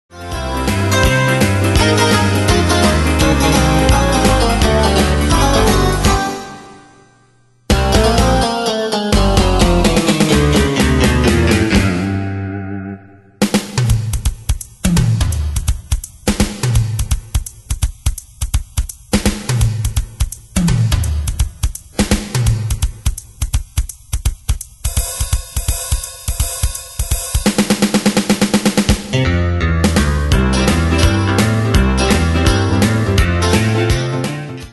Style: Country Année/Year: 1999 Tempo: 168 Durée/Time: 2.26
Danse/Dance: RockNRoll Cat Id.
Pro Backing Tracks